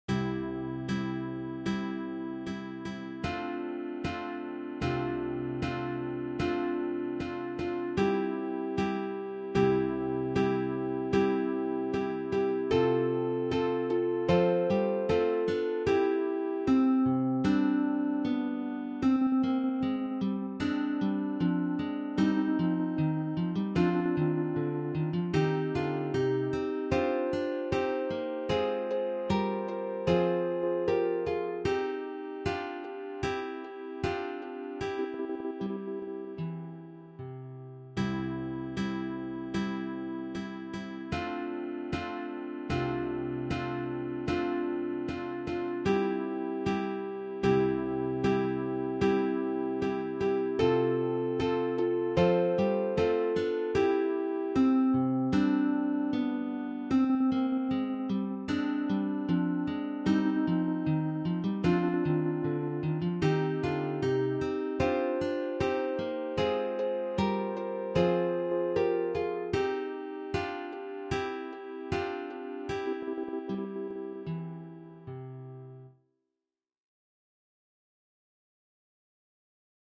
Genre-Stil-Form: geistlich
Tonart(en): C-Dur